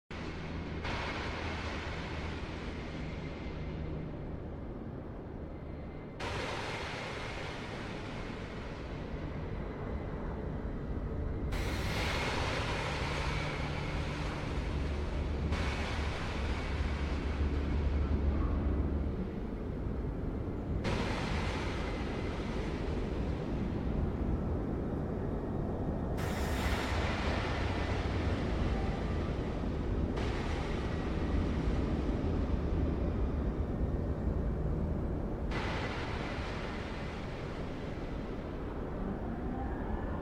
bad weather in all cities sound effects free download